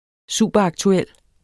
Udtale [ ˈsuˀbʌˈ- ]